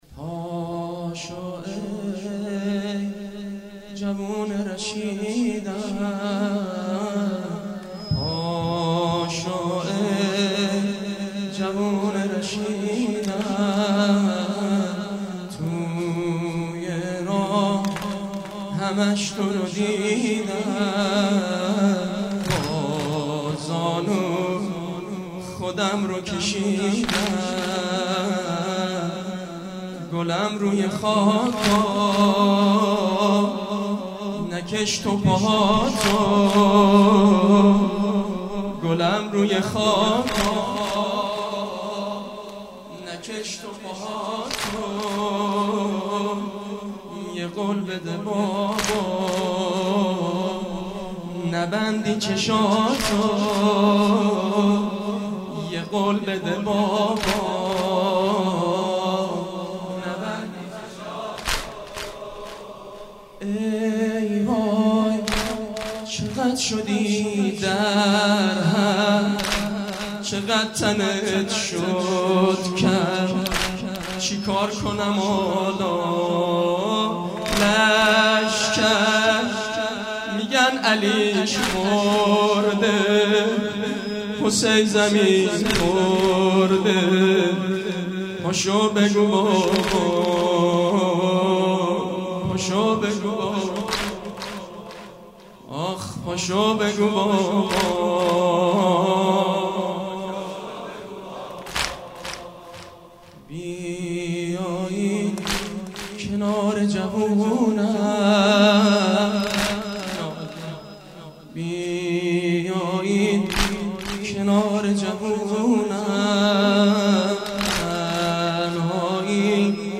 همه چی عالیه فقط یه انتقاد کوچولو : چرا کیفیت مداحیا ایقد پایینه ؟؟؟؟؟؟؟؟؟؟؟؟؟؟؟